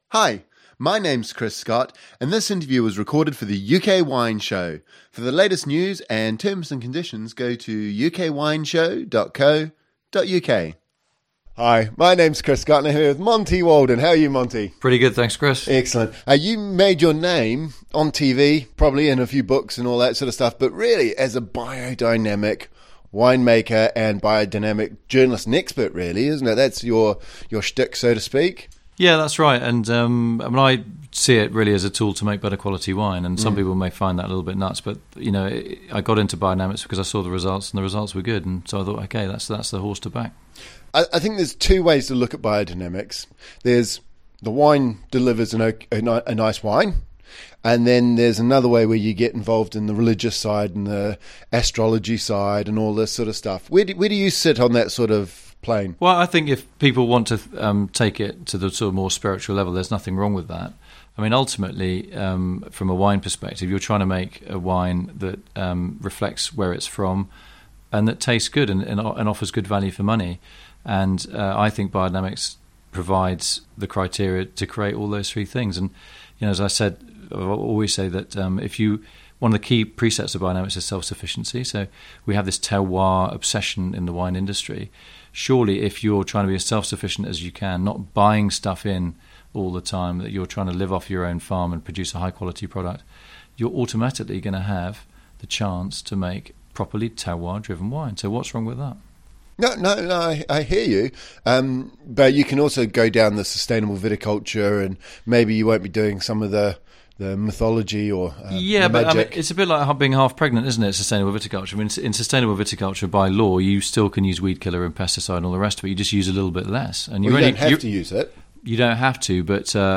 In this interview we talk about biodynamic principles and practices and debate biodynamic/organic vs sustainable wine-making. We hear about different methods of biodynamic farming and the different preparations used, such as horn manure, as well as some of the more spiritual aspects that as a biodynamic winemaker you really need to buy into.